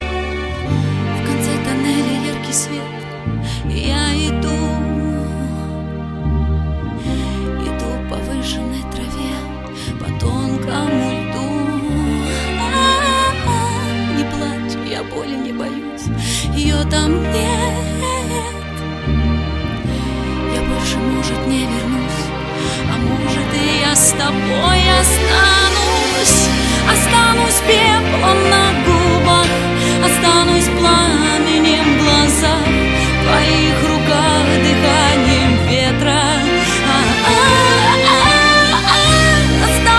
рок , pop rock